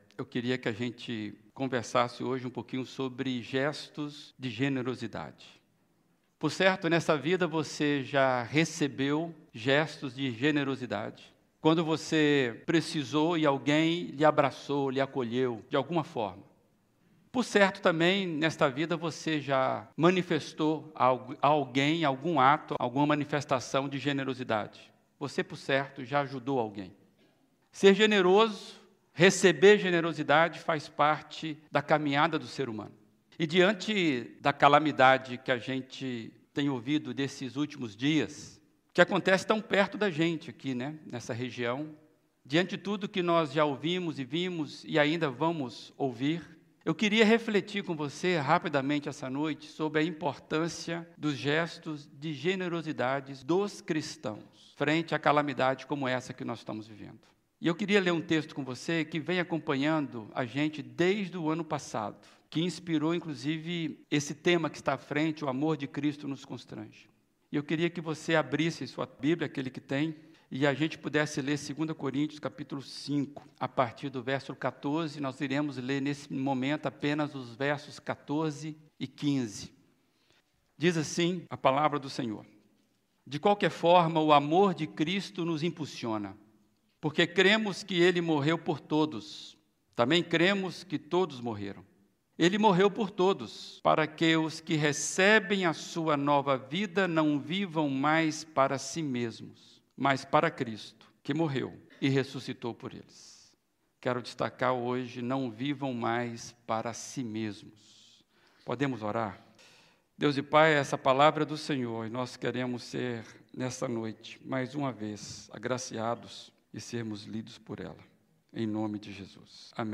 Primeira Igreja Batista de Brusque